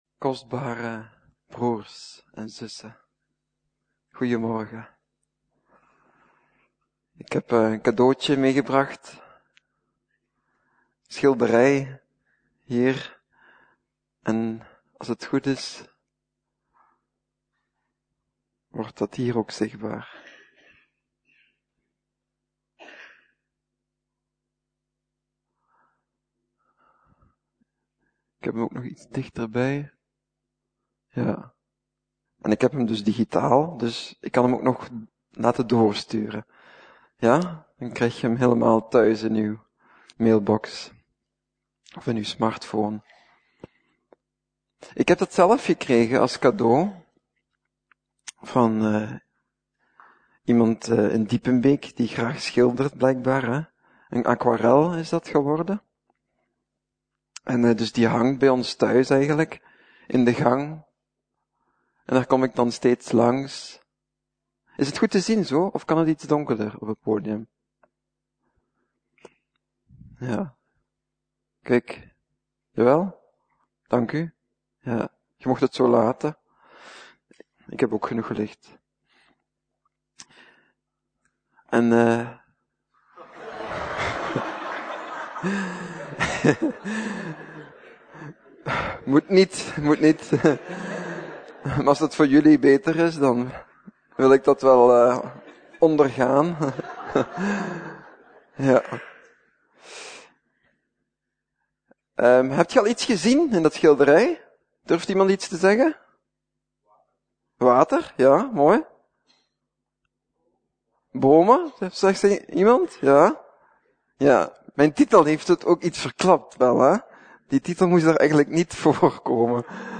Preek: Boom aan het water - Levende Hoop